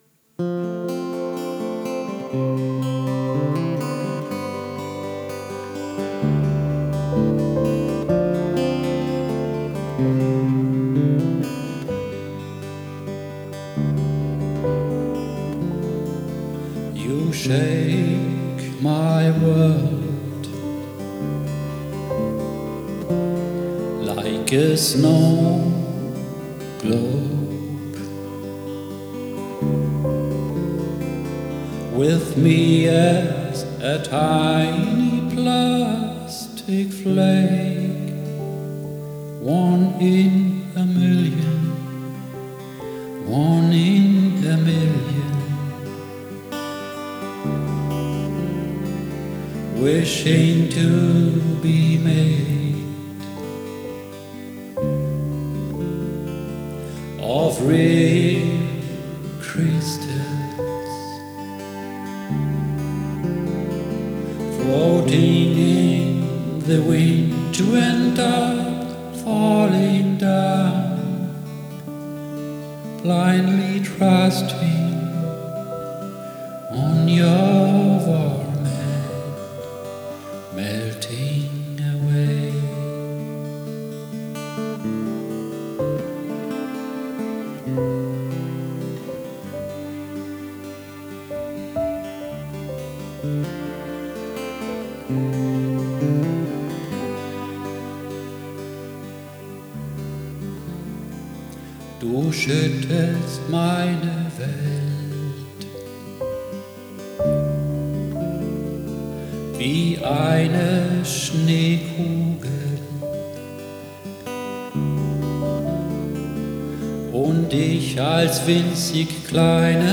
Die Aufnahmequalität ist nämlich nicht sonderlich gut. Aufgenommen mit einem einfachen Mikrofon zu Hause, sind die Tiefen und Nuancen über einfache Abspielgeräte, wie Handy oder Tablet, nicht sonderlich zu hören.
Einiges klingt auch schief, hatte dann aber keine Lust, alles noch einmal aufzunehmen.